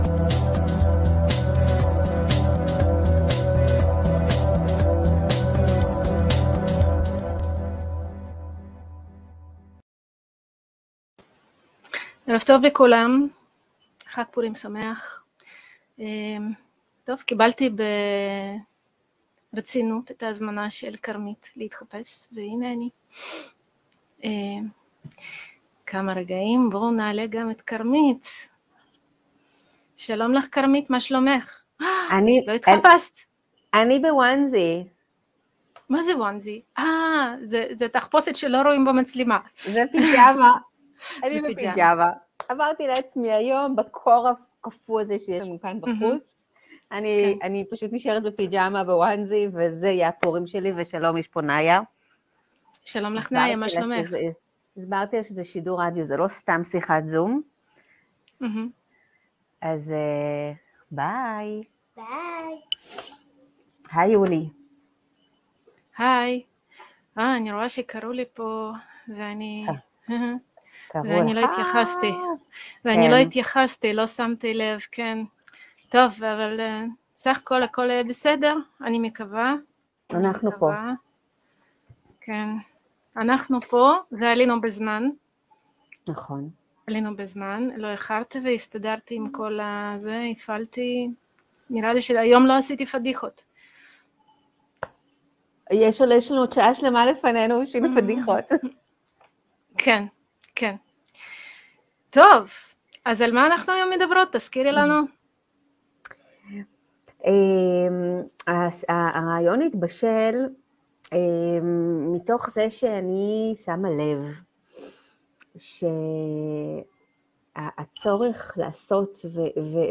שידור שני שלנו ברדיו חופש, 15/3/22